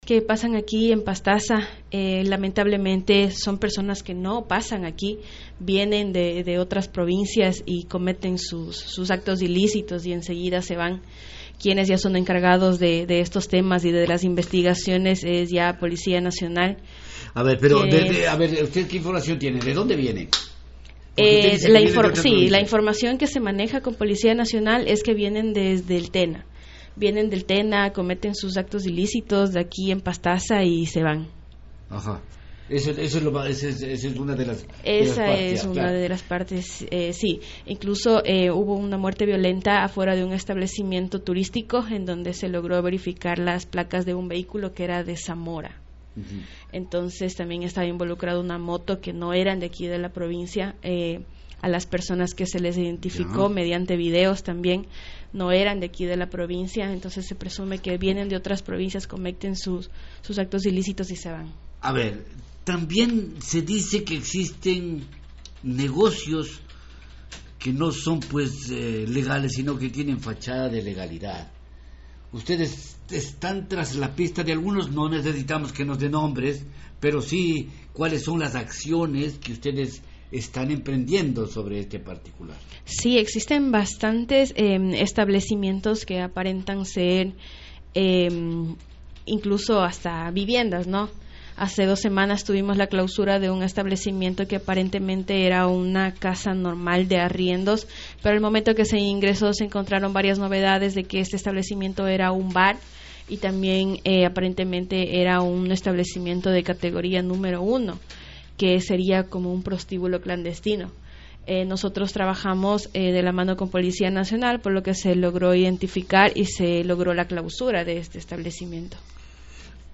ENTREVISTA: Karla Martínez, Intendente General de Policía Pastaza.